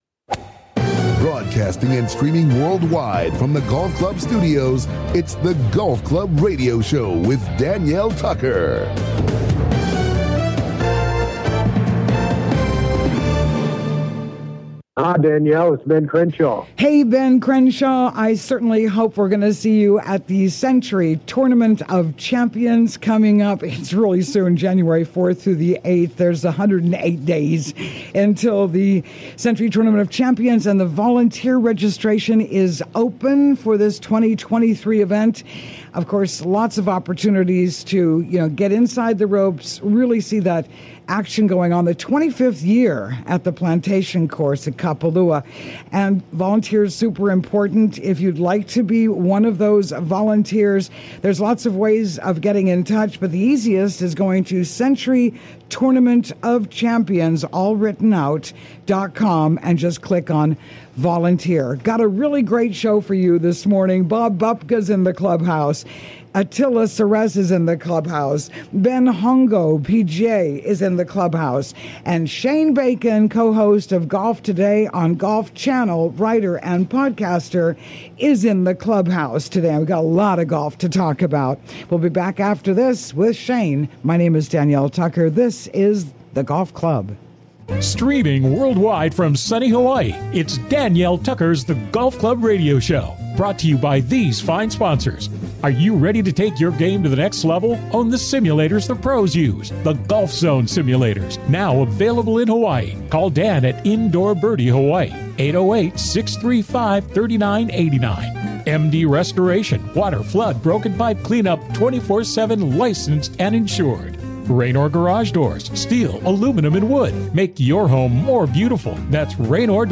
COMING TO YOU LIVE FROM THE GOLF CLUB STUDIOS ON LOVELY OAHU � WELCOME INTO THE CLUBHOUSE!